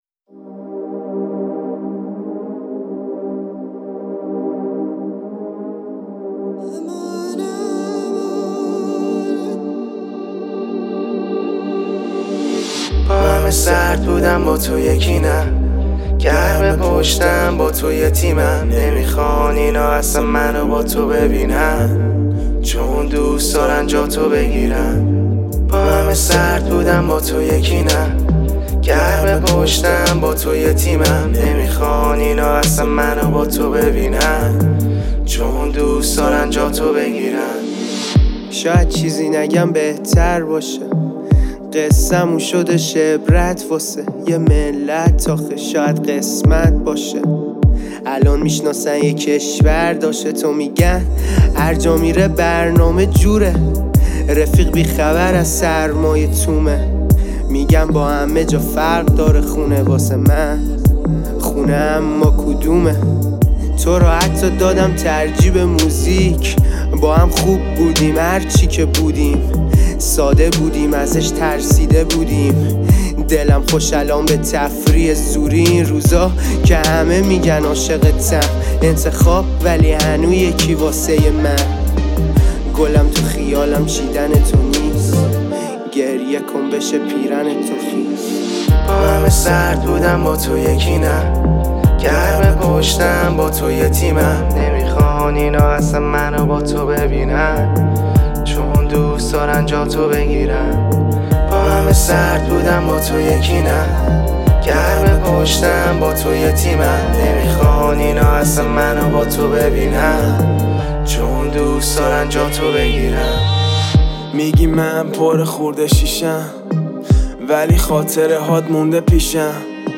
رپ
آهنگ با صدای زن